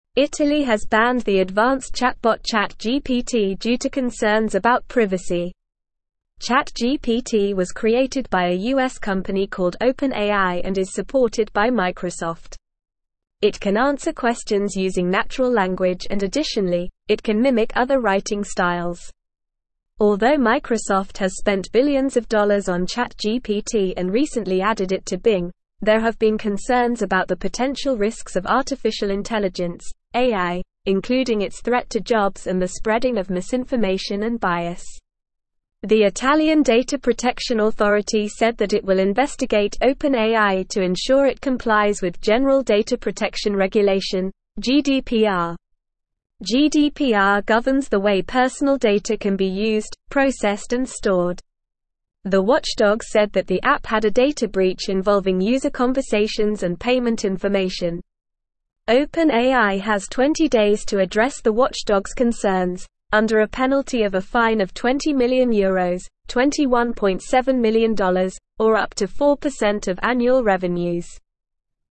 Normal
English-Newsroom-Beginner-NORMAL-Reading-Italy-Bans-Chatting-Robot-Over-Privacy-Worries.mp3